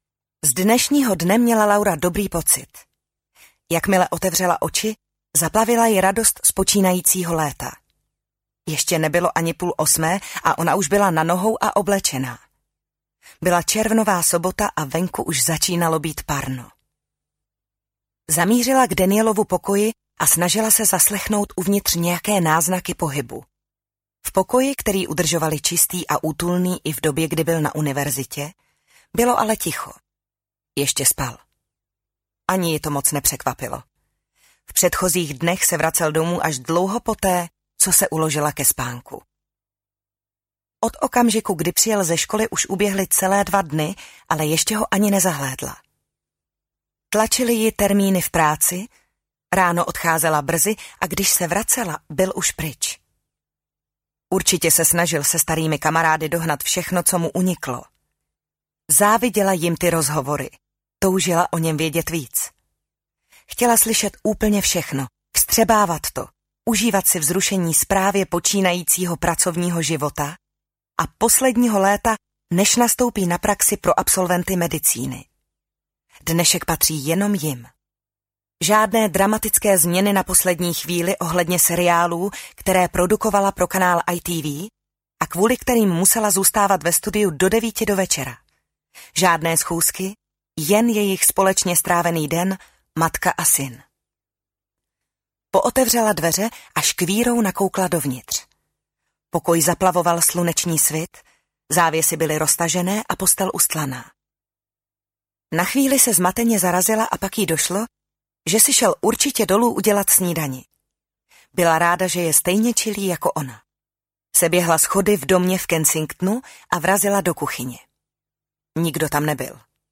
Přítelkyně audiokniha
Ukázka z knihy